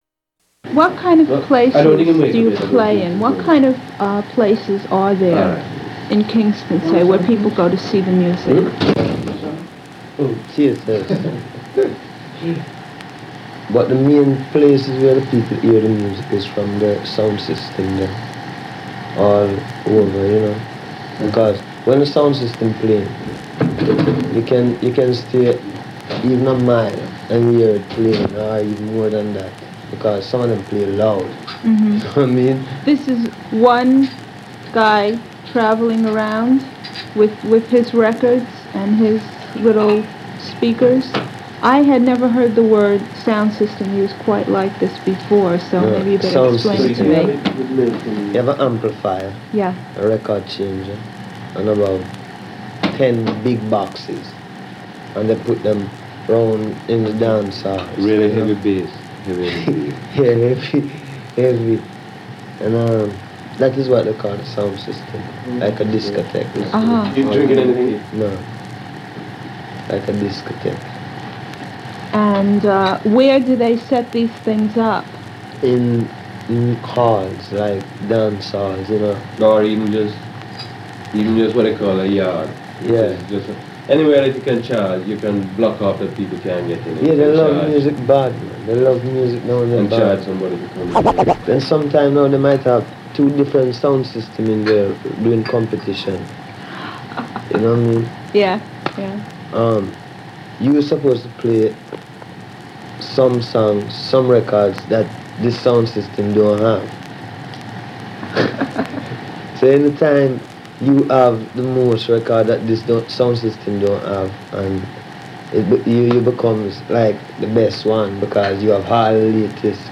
classic reggae/dancehall mix CD
Reggae/Dub